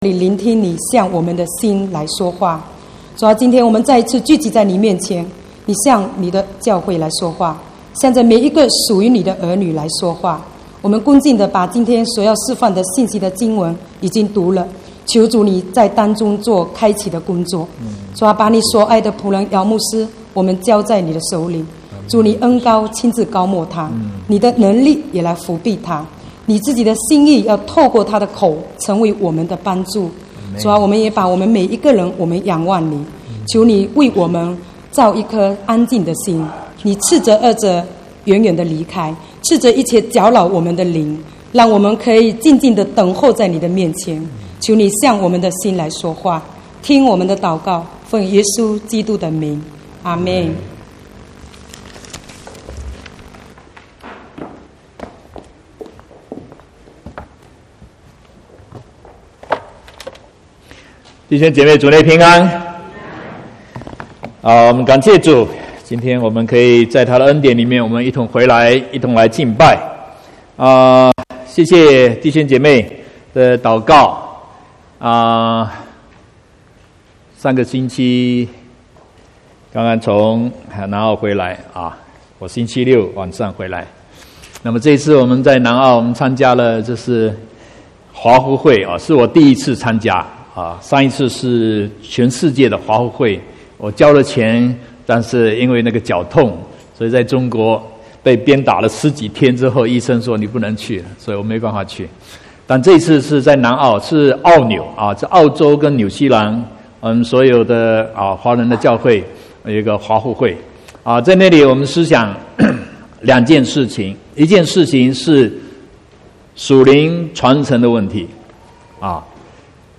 31/3/2019 國語堂講道